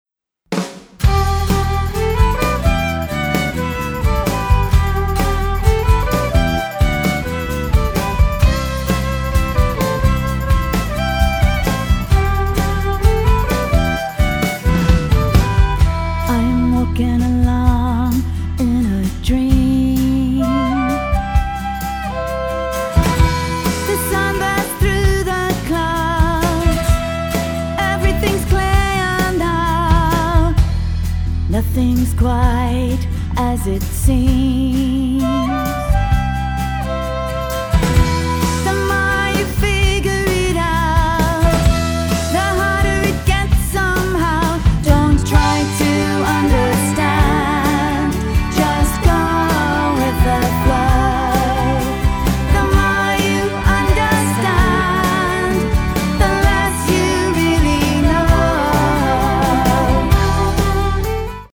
Another driving number